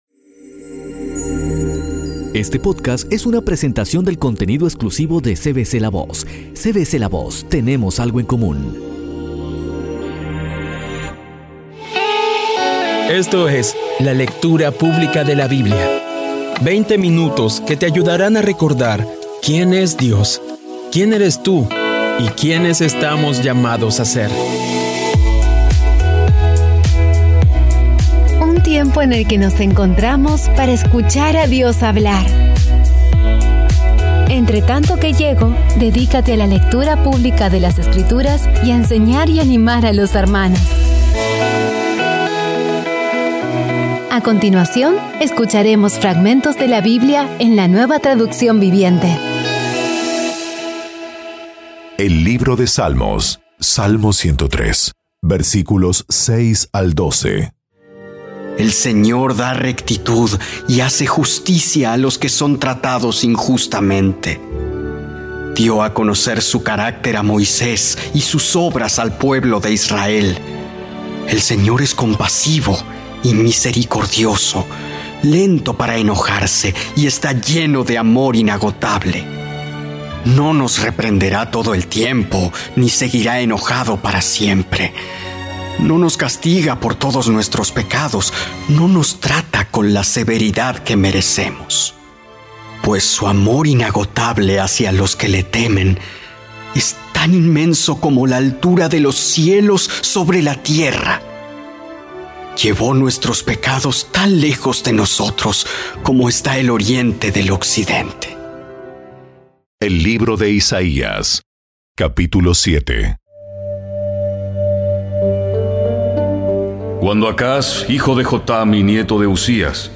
Audio Biblia Dramatizada Episodio 249
Poco a poco y con las maravillosas voces actuadas de los protagonistas vas degustando las palabras de esa guía que Dios nos dio.